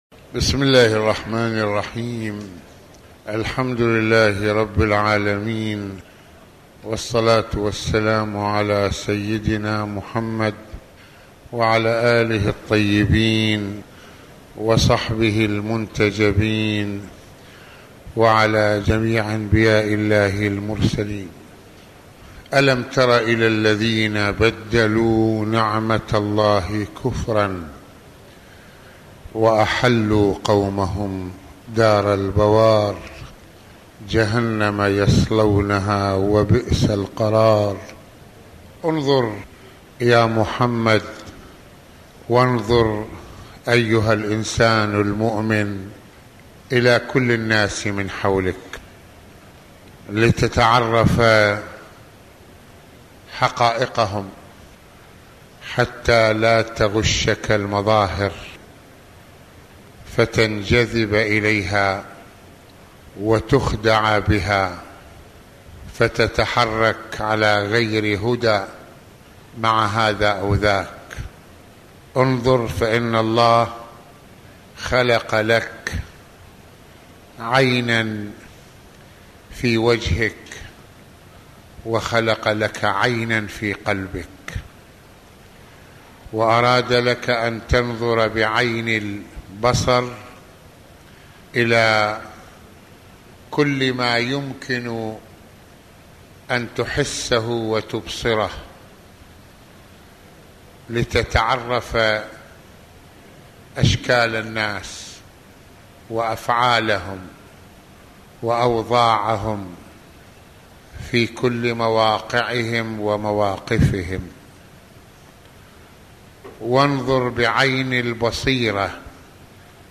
موعظة ليلة الجمعة المكان: مسحد الإمامين الحسنين (ع)